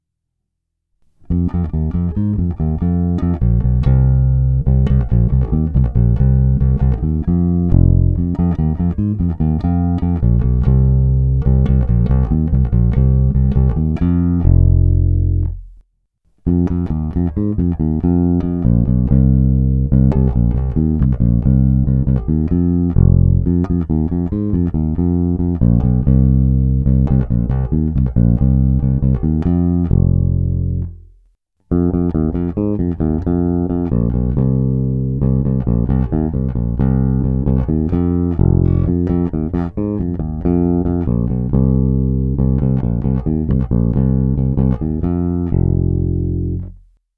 Je hutný, vrčivý, zvonivý, s bohatými středy, s příjemnými výškami a masívními basy.
Není-li uvedeno jinak, následující ukázky jsou pořízeny rovnou do vstupu zvukové karty a kromě normalizace ponechány bez jakéhokoli postprocesingu.